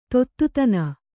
♪ tottutana